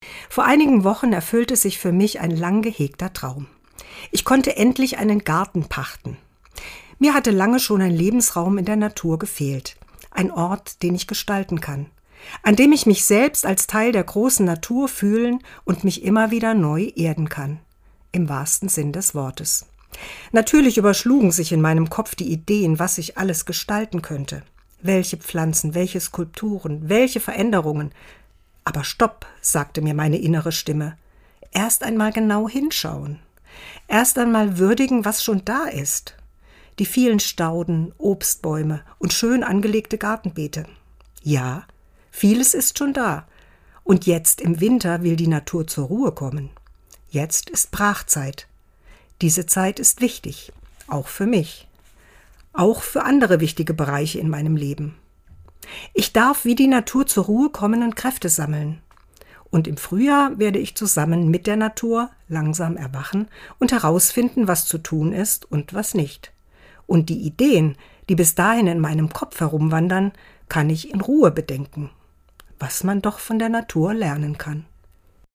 Dezember 2024, Autorin und Sprecherin ist